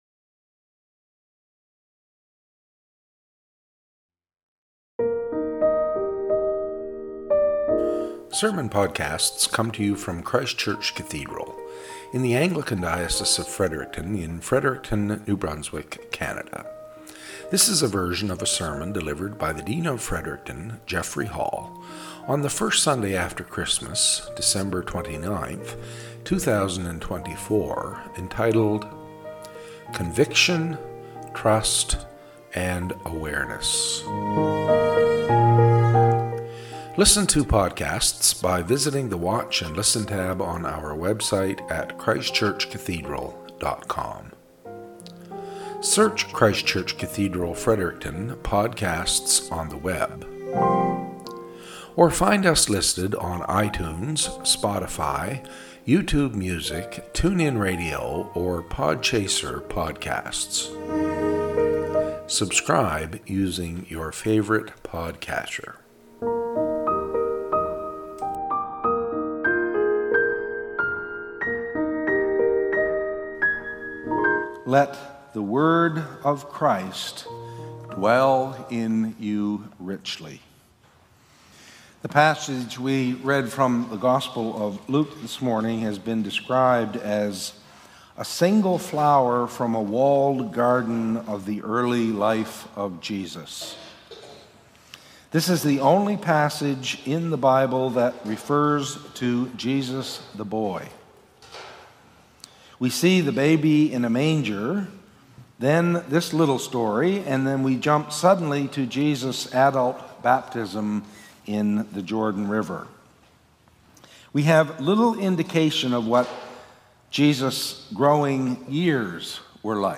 Podcast from Christ Church Cathedral Fredericton